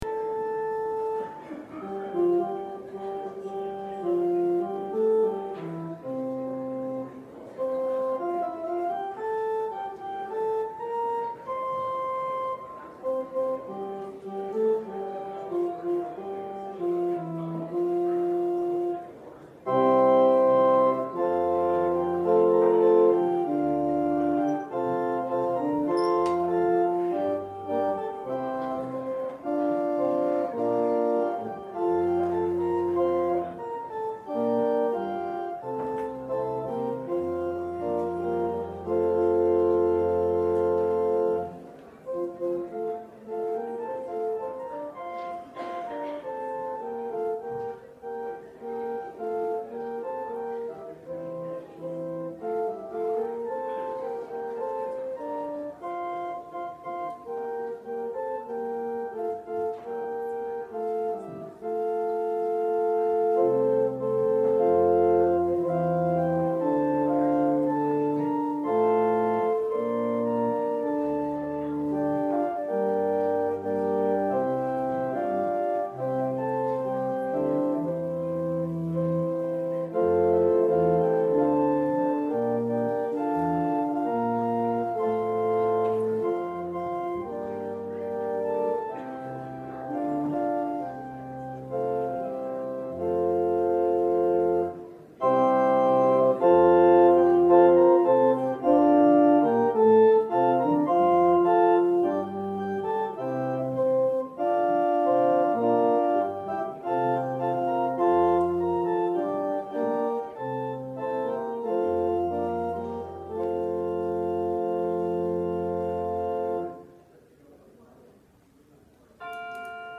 14 Service Type: Sunday Worship Topics: Forgivness , Grace , pardon « Salvation